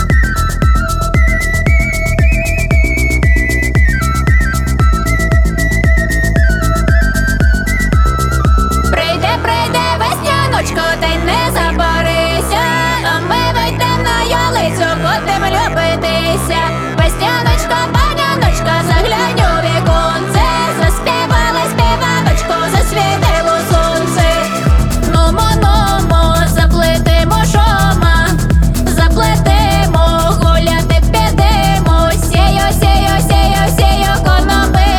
Жанр: Фолк-рок / Альтернатива / Украинские
# Alternative Folk